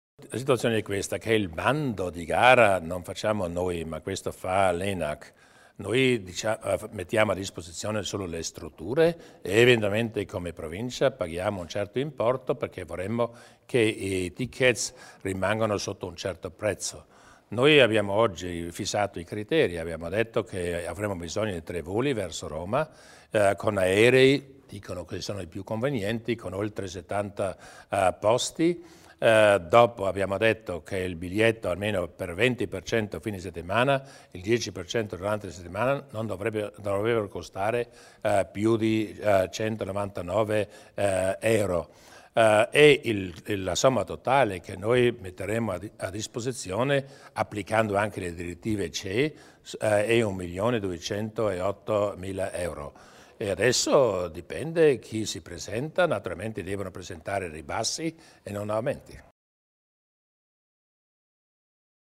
Il Presidente Durnwalder illustra i progetti per l'aeroporto di Bolzano